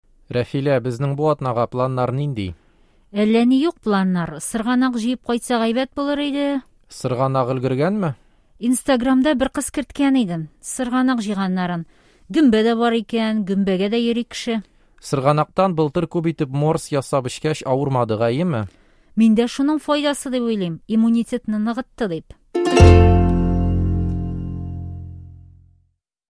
Диалог: Сырганак